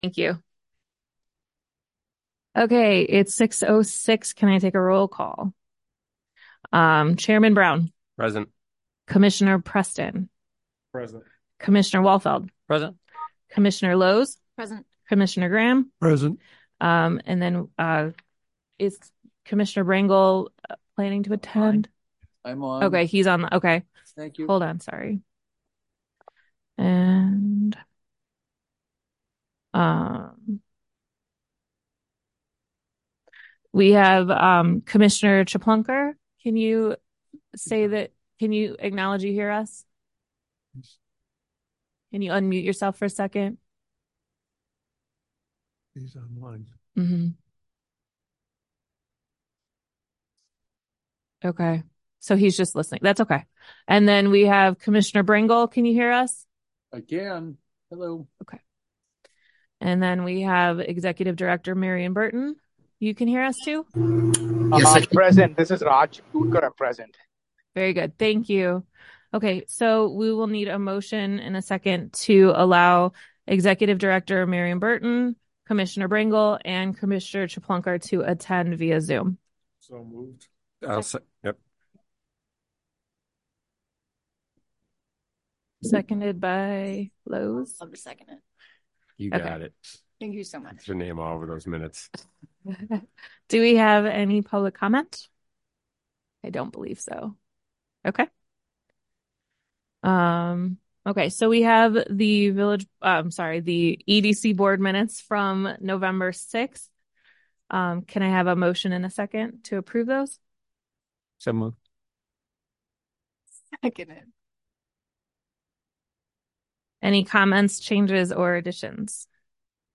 Economic Development Commission Meeting
Village Hall - 400 Park Avenue - River Forest - IL - 1st Floor - Community Room